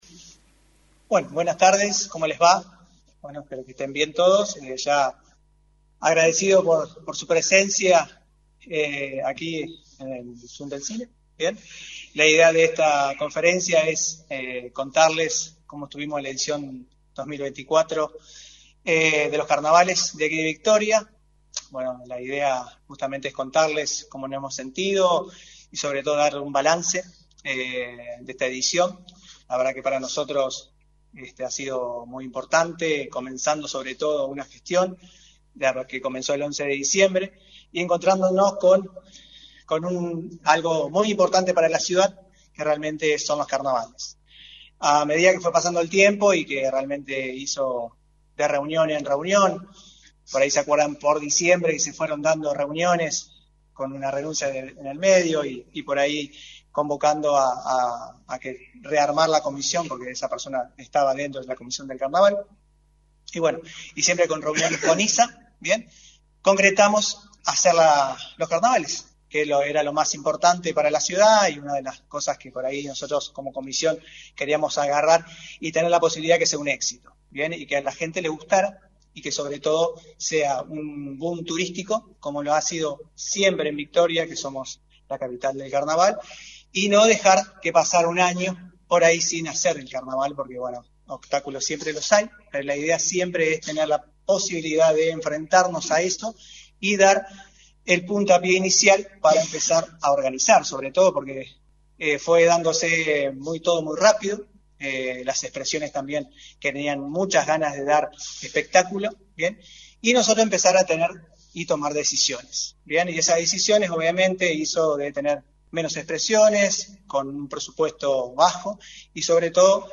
La Comisión de Carnaval presentaron este lunes, en rueda de prensa, el primer balance de la última edición de los Carnavales victorienses.
Conferencia-de-prensa-BAlance-de-carnaval.mp3